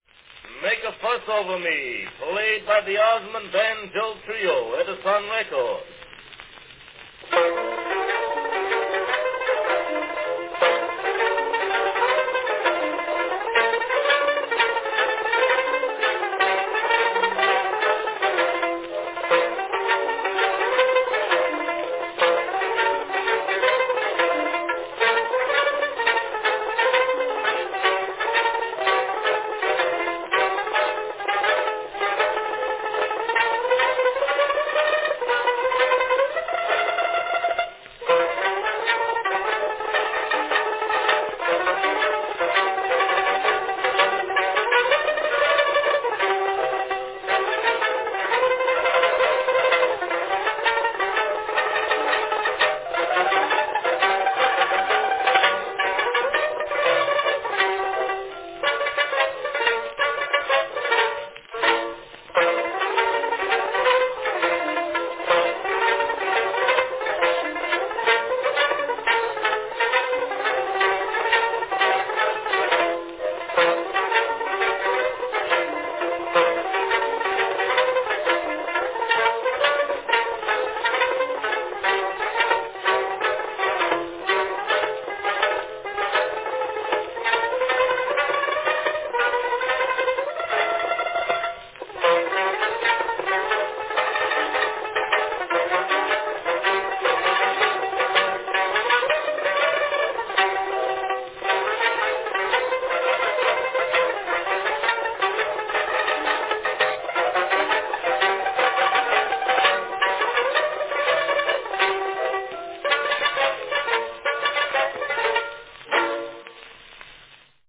A banjo trio performance from 1905
Category Banjo trio
The three banjos are plainly heard.